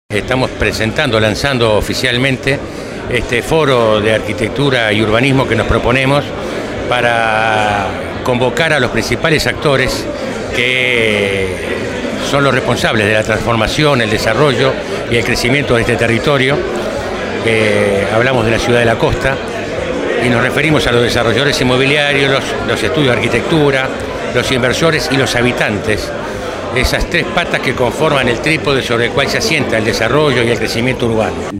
El martes 19 de julio, se realizó el lanzamiento del Foro de Arquitectura y Urbanismo, organizado por la Editorial AyD y promovido por la Intendencia de Canelones a través de la Agencia de Promoción a la Inversión (API).